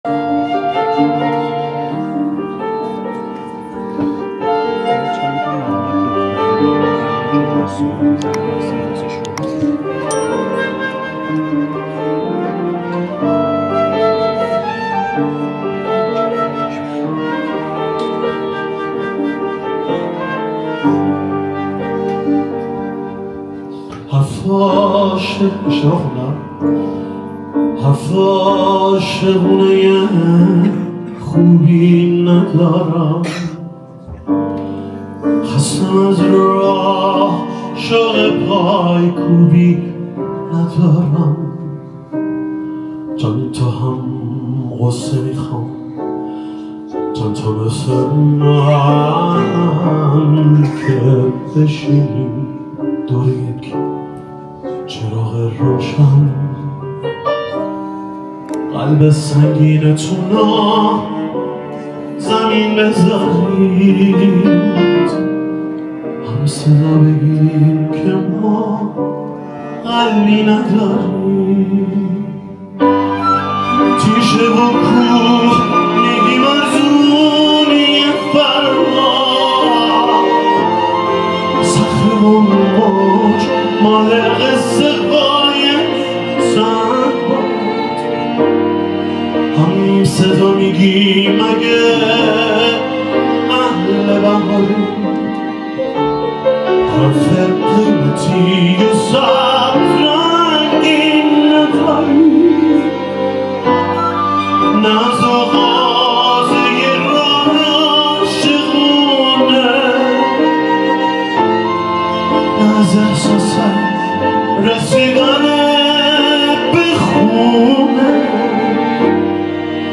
آهنگ پاپ